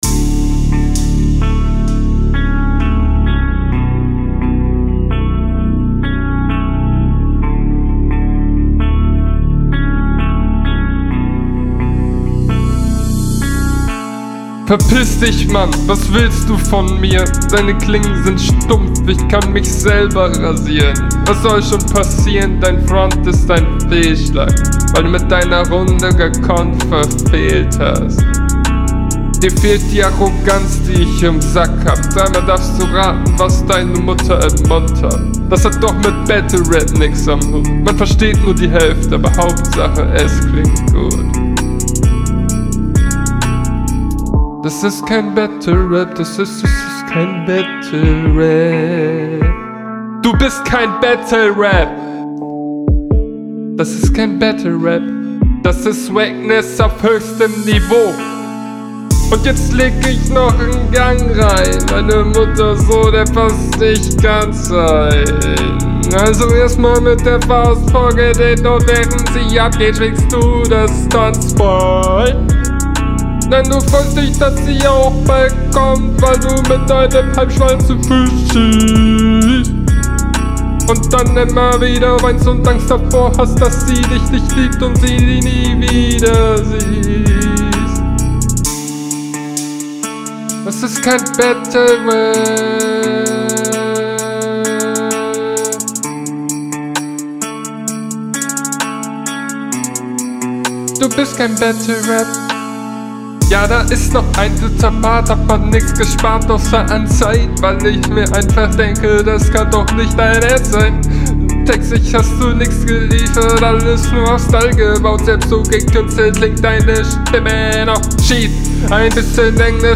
Ich glaube wenn du den Text deutlich schneller gerappt hättest wäre es ganz cool geworden.